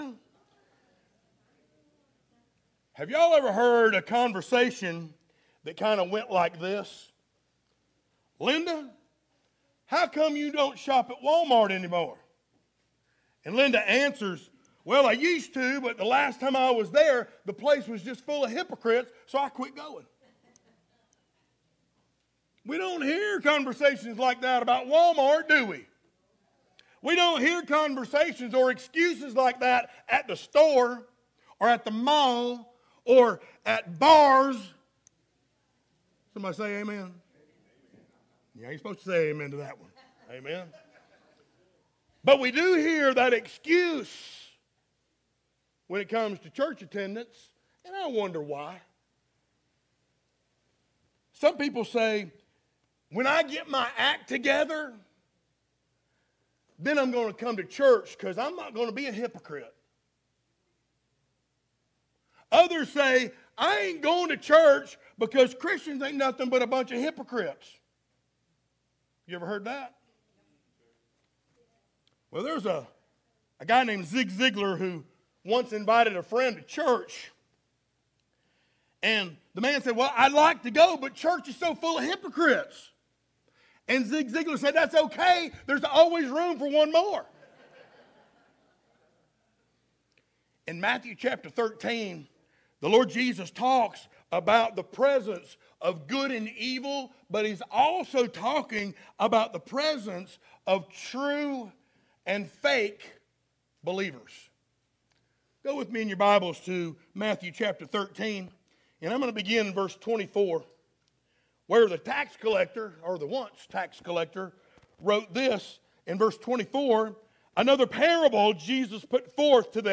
sermons Passage: Matthew 13:24-43 Service Type: Sunday Morning Download Files Notes « God’s Way Works!